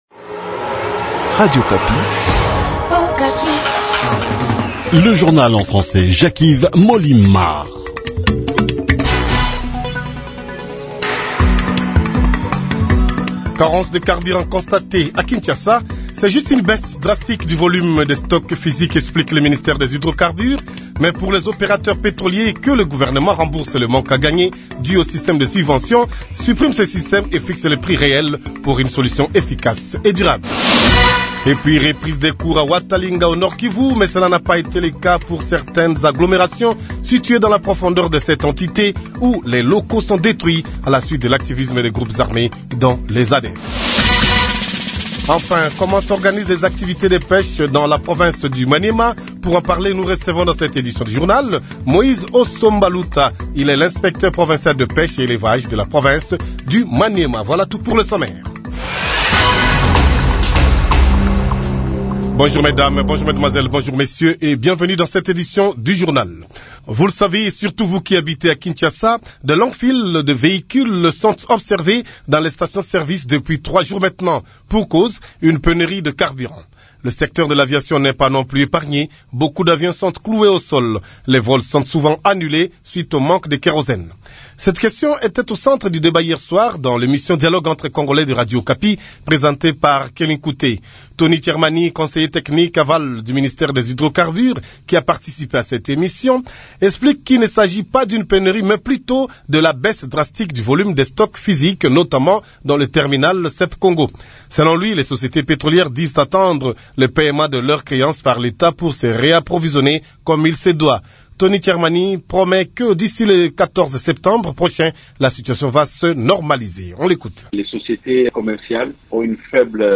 KIN : Reportage sur terrain concernant pénurie de carburant